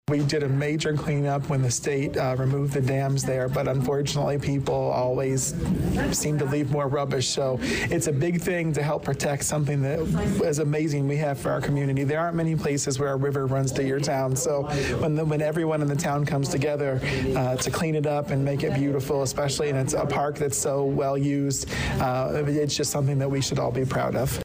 Danville mayor Rickey Williams, Jr reminds us of the importance of this.